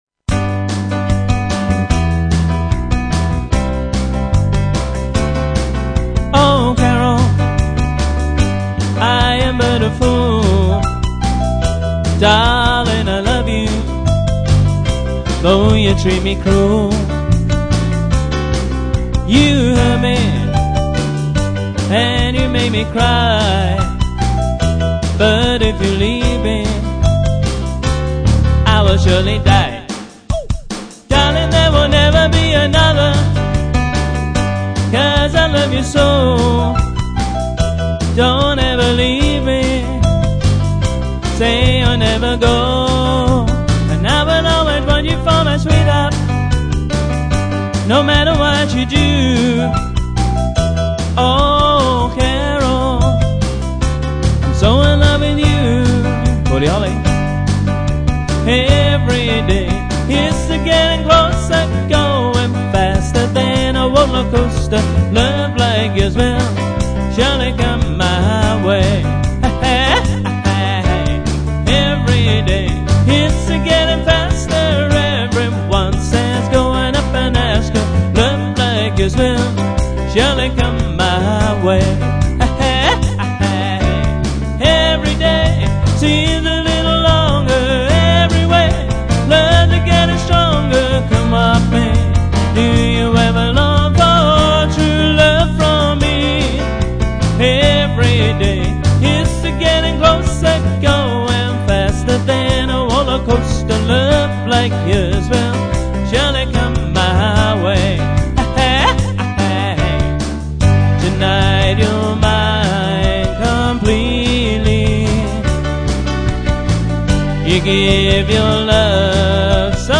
Roland RD700 piano, drum machine & vocals